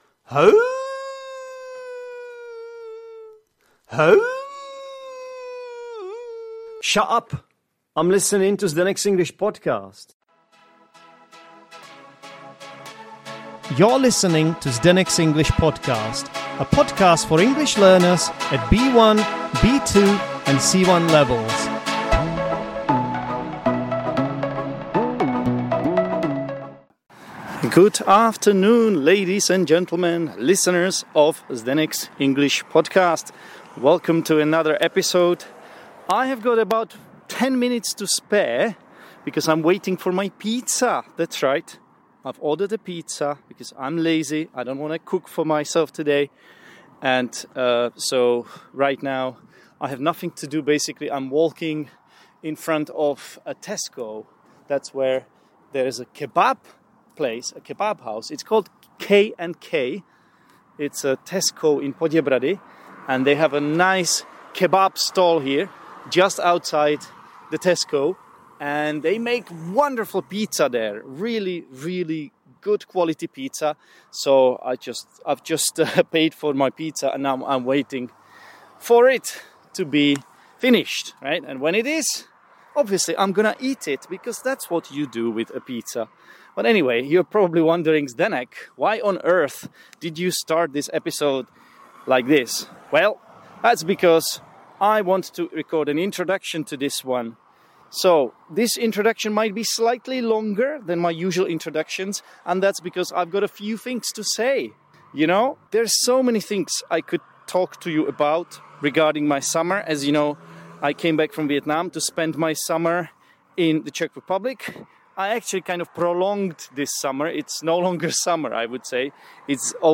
This was an improvised recording, so it's quite lighthearted and touches on a variety of topics, which is reflected in the episode's title.